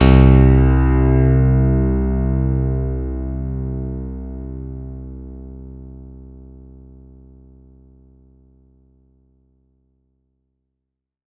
R  C1  DANCE.wav